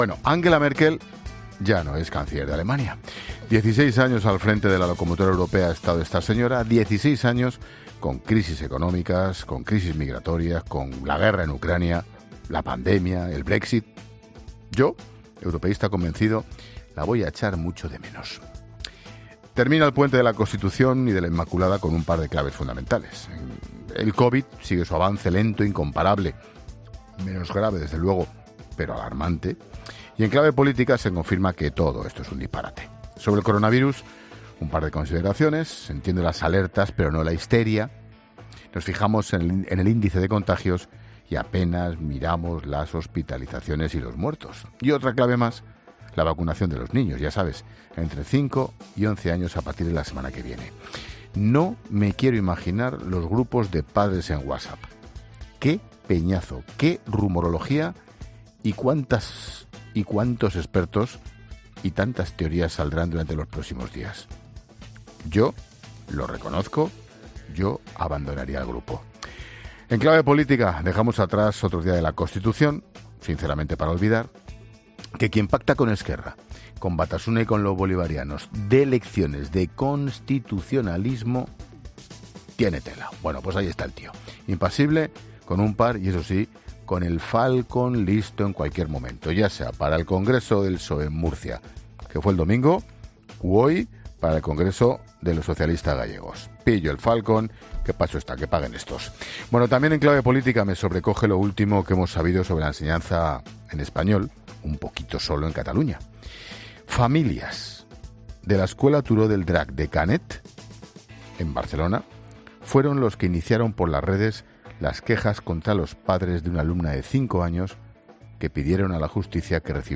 Monólogo de Expósito
El director de 'La Linterna', Ángel Expósito, analiza las principales noticias de este miércoles 8 de diciembre